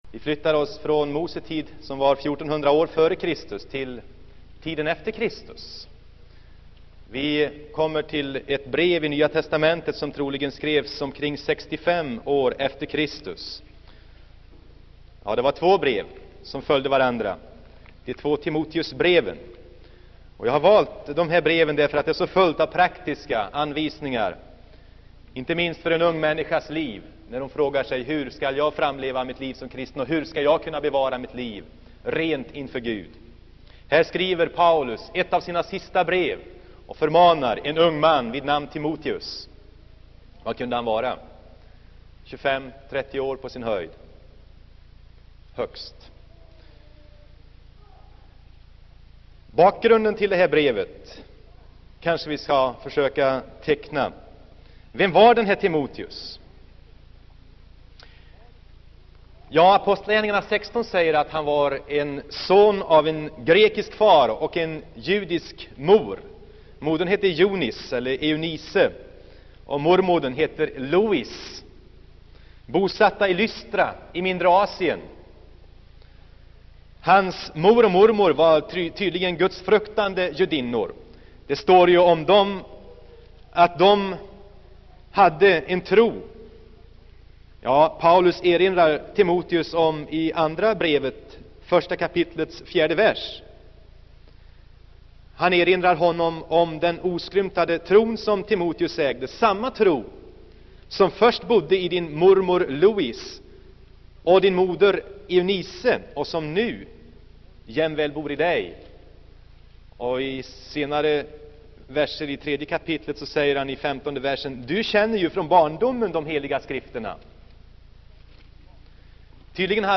Inspelad i Saronkyrkan, Göteborg 1976-11-21.